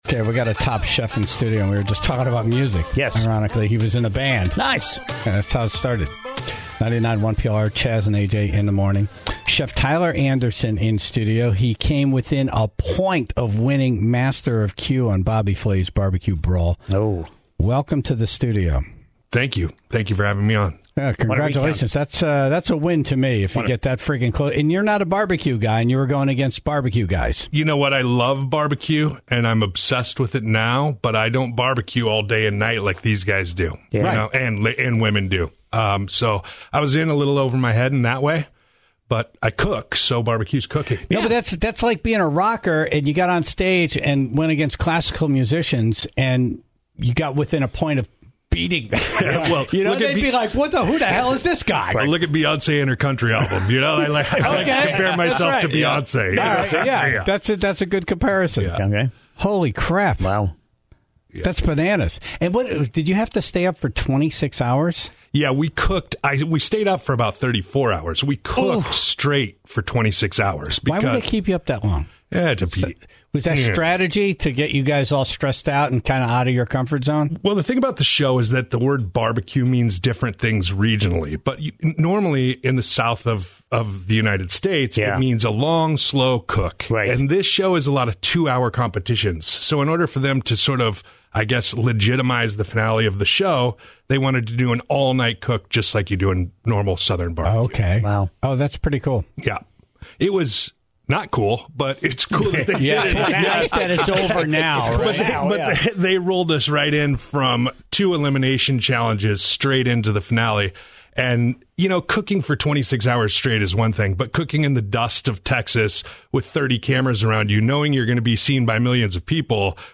in studio this morning